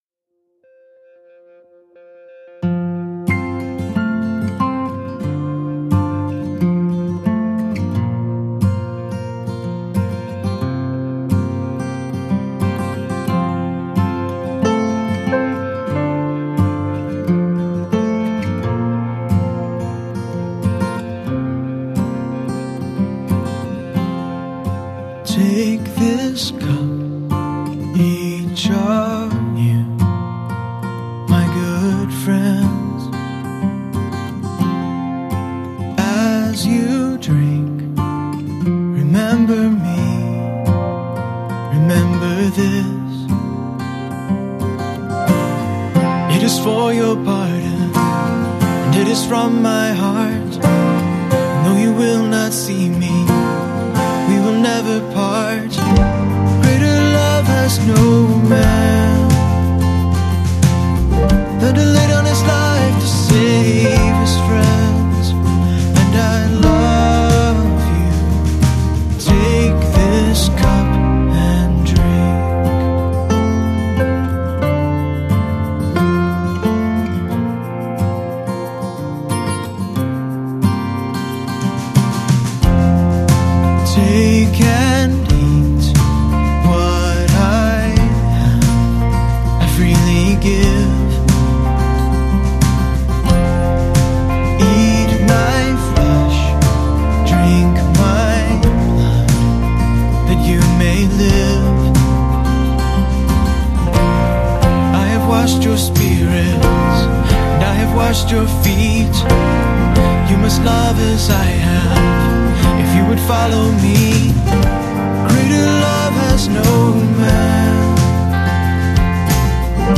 lead vocals, background vocals, acoustic guitars, harmonica
drums, percussion
bass guitar
electric guitars
piano, organ, rhodes, keys
accordian, mandolin, high strung guitar